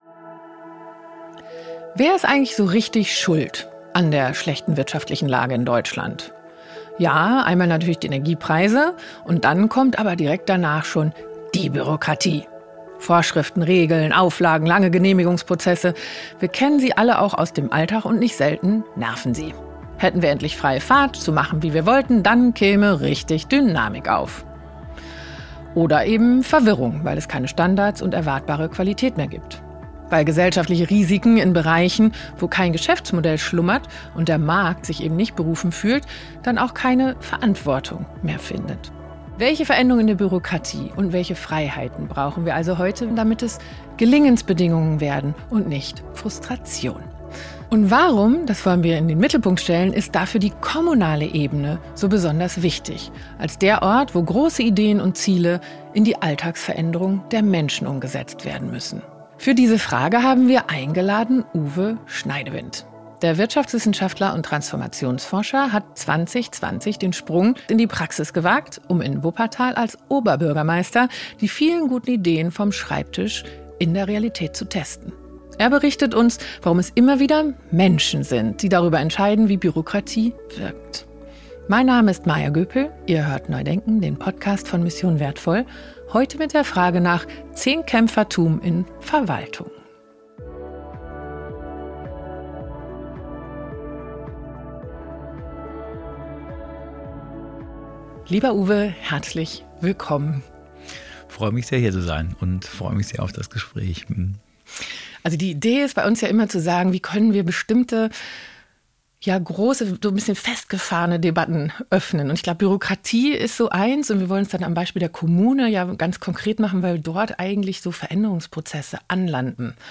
In dieser Folge von NEU DENKEN spricht Maja Göpel mit Uwe Schneidewind darüber, wie Bürokratie so gestaltet werden kann, dass sie dem Gelingen dient. Und warum gerade die kommunale Ebene zeigt, dass es nicht nur auf die Regeln selbst ankommt – sondern oft darauf, wie Menschen mit ihnen umgehen.